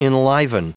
Prononciation du mot enliven en anglais (fichier audio)
enliven.wav